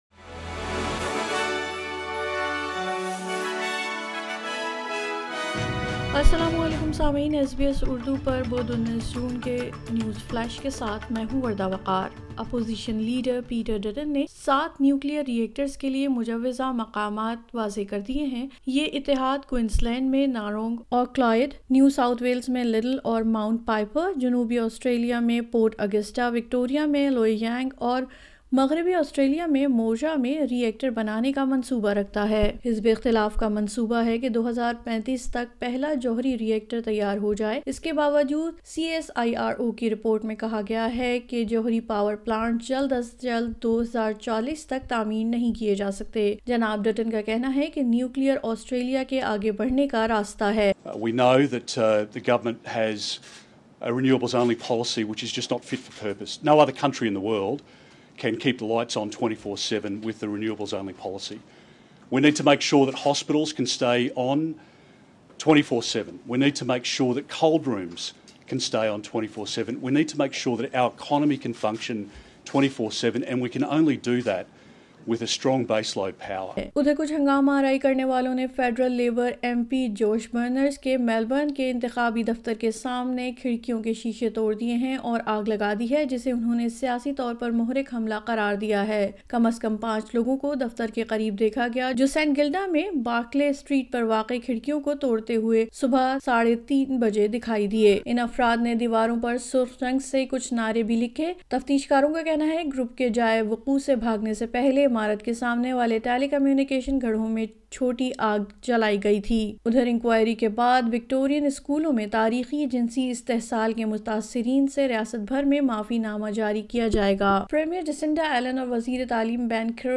نیوز فلینش:19 جون 2024:پیٹر ڈٹن نے نیو کلئیر ری ایکٹرز کے لئے جگہوں کا تعین کر دیا